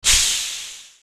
Could someone convert this dumpvalve for me?
sounds more like ur losing air from ur tire lol